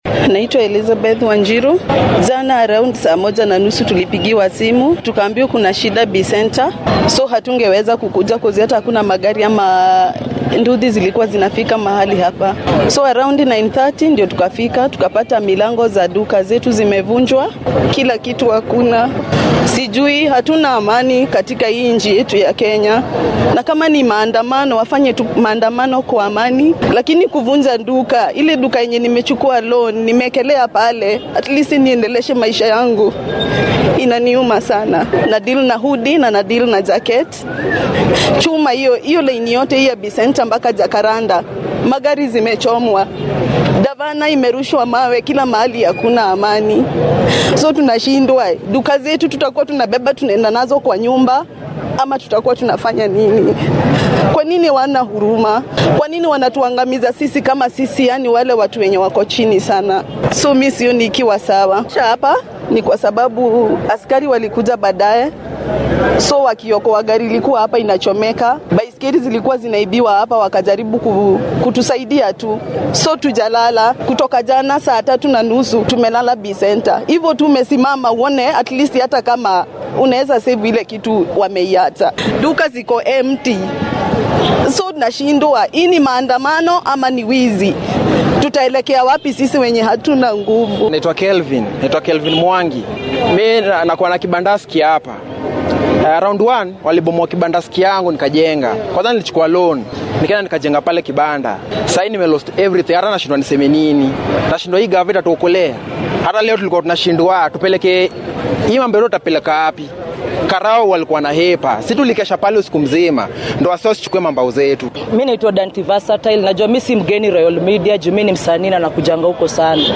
Tuugada ayaa sidoo kale saaka iskudayday in dhac ka gaystaan xarumihii ay shalay ay weerareen. Qaar ka mid ah dadka xarumahoodi ganacsi loo dhacay ayaa warbaahinta dareenkooda la wadaaga